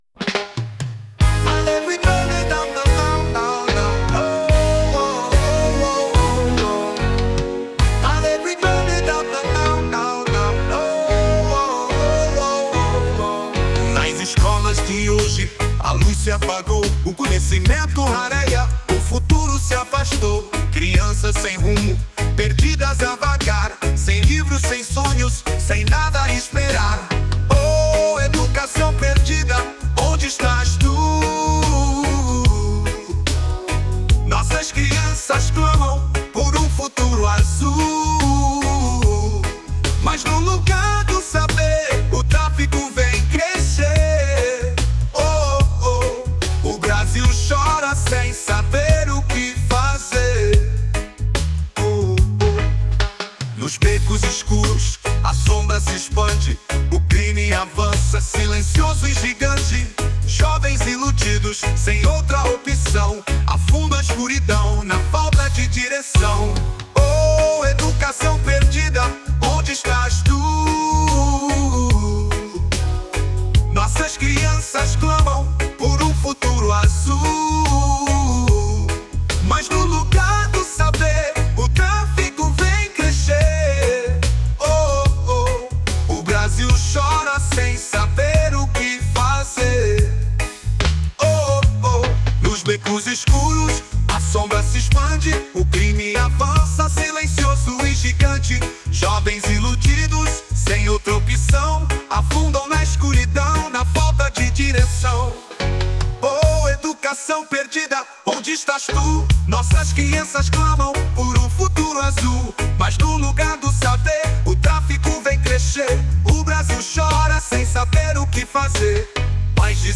2024-07-22 12:37:30 Gênero: Reggae Views